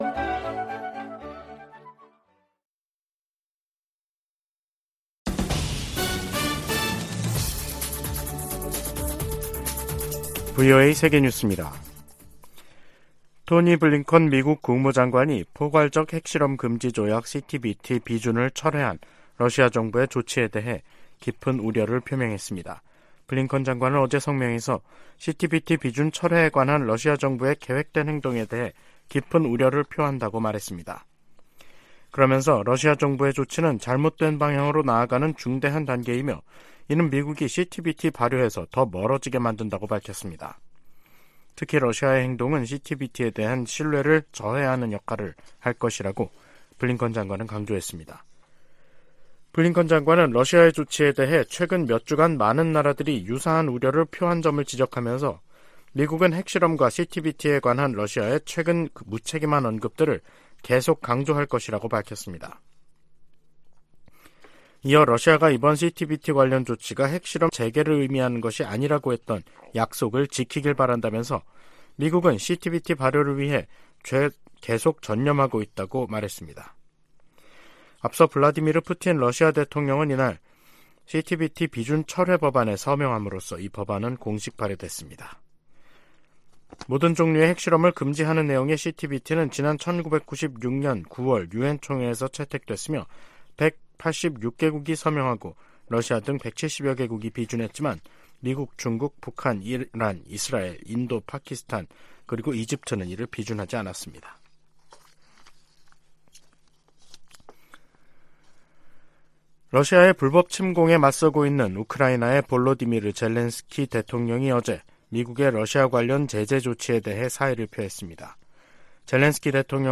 VOA 한국어 간판 뉴스 프로그램 '뉴스 투데이', 2023년 11월 3일 2부 방송입니다. 다음 주 한국을 방문하는 토니 블링컨 미 국무장관이 철통 같은 방위 공약을 강조할 것이라고 국무부가 밝혔습니다. 로이드 오스틴 국방장관도 잇따라 한국을 방문합니다.